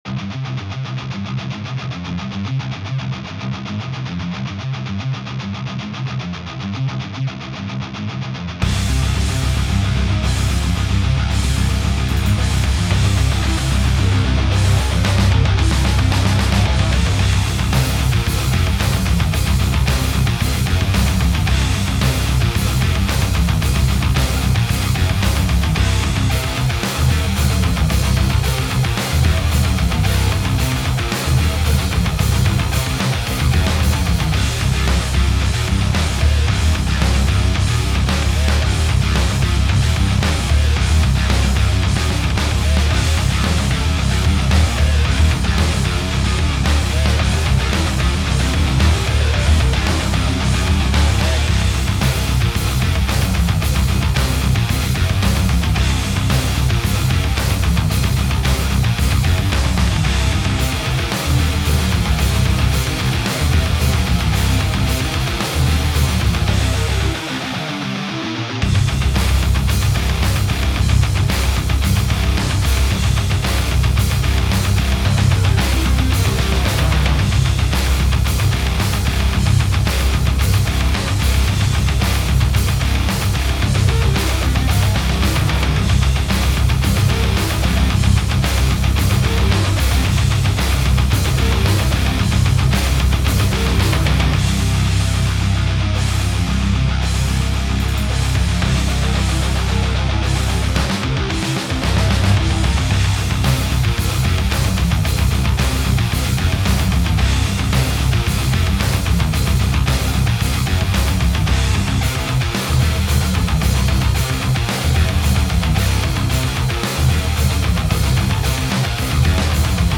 メタル風にすることで噴火のような派手な印象を狙っている。
タグ: かっこいい 戦闘曲 激しい/怒り コメント: 火山洞窟での強敵との戦闘シーンをイメージして作った楽曲。